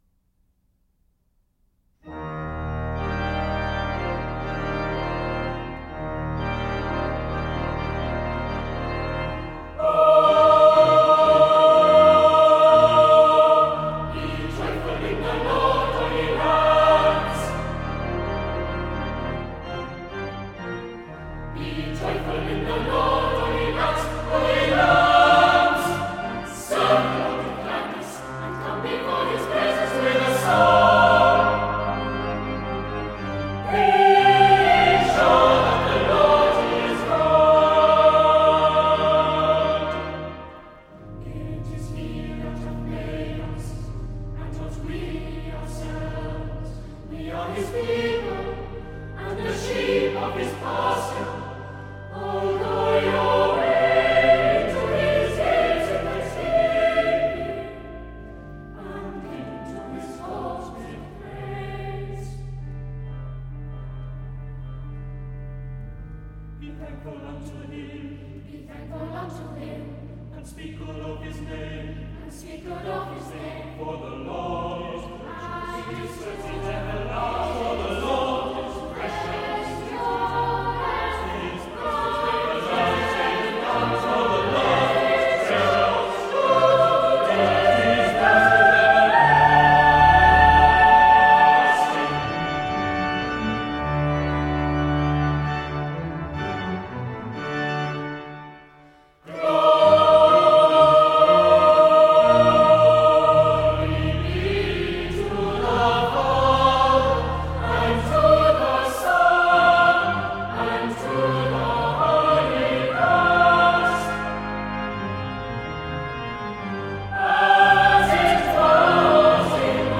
choral music